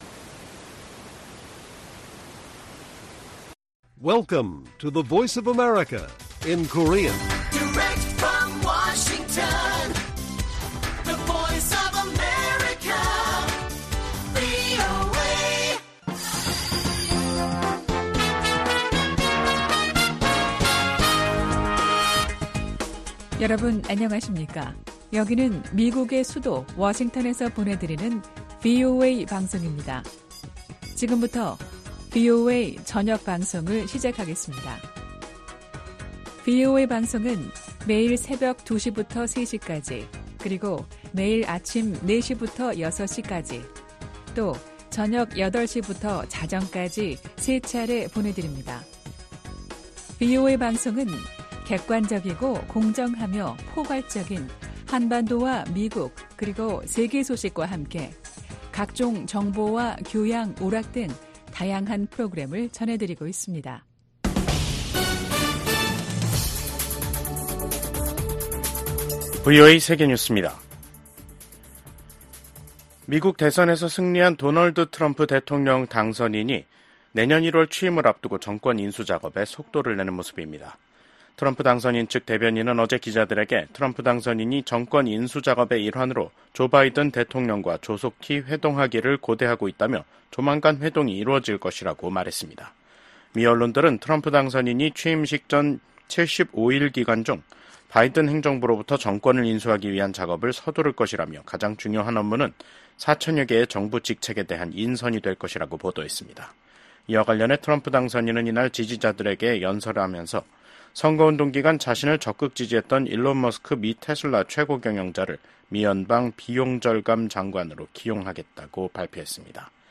VOA 한국어 간판 뉴스 프로그램 '뉴스 투데이', 2024년 11월 7일 1부 방송입니다. 제47대 대통령 선거에서 도널드 트럼프 전 대통령에게 패한 카멀라 해리스 부통령이 패배를 공개적으로 인정했습니다. 윤석열 한국 대통령은 도널드 트럼프 미국 대통령 당선인과 전화통화를 하고 양국의 긴밀한 협력관계 유지에 공감했습니다. 도널드 트럼프 대통령 당선인이 2기 행정부에서 첫 임기 때와 다른 대북 접근법을 보일 수도 있을 것으로 전문가들은 전망했습니다.